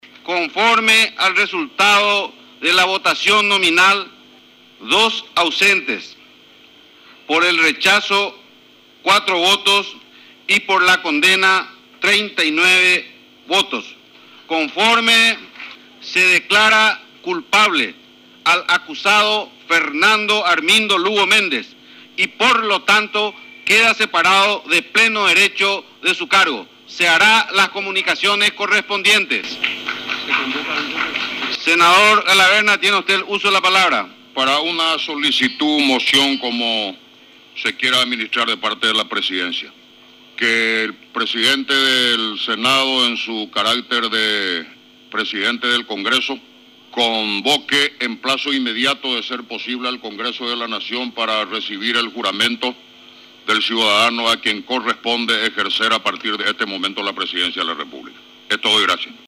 Lectura de los resultados de la votación que destituyó al presidente